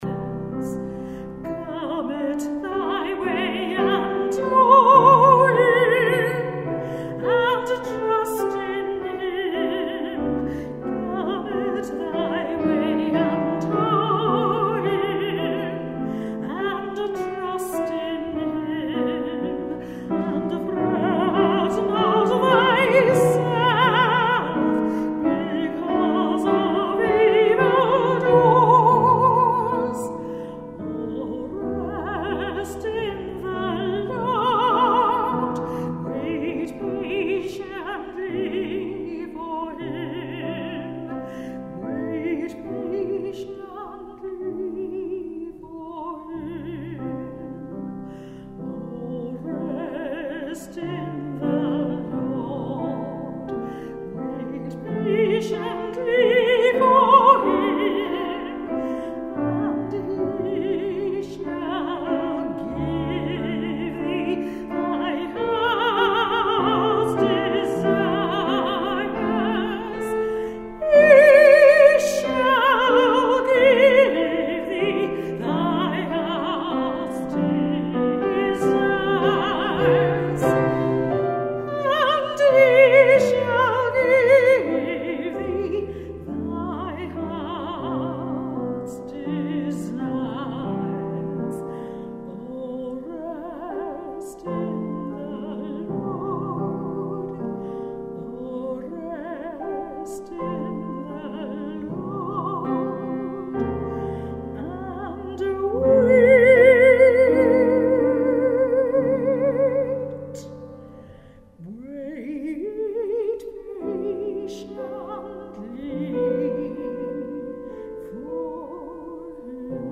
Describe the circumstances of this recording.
Recorded at the Crypt Canterbury Cathedral April 12th 2010.